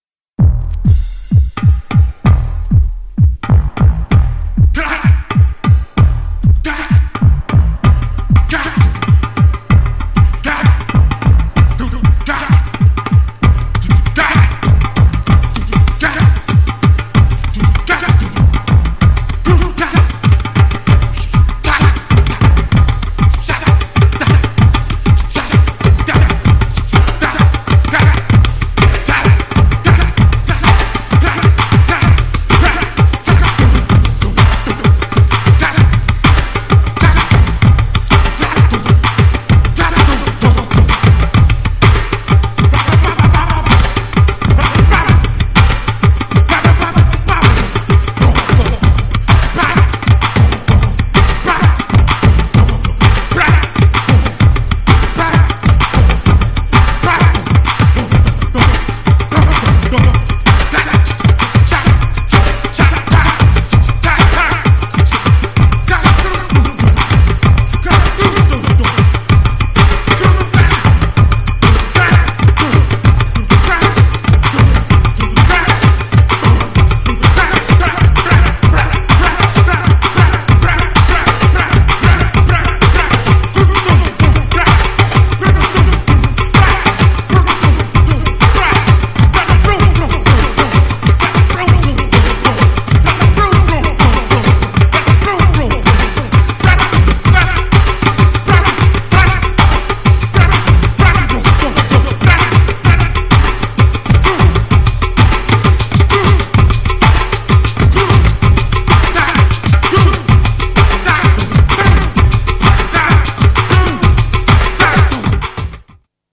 呪術的なアフロパーカッションHOUSE!! タイトル通りの6/8の変速リズム!!
GENRE House
BPM 126〜130BPM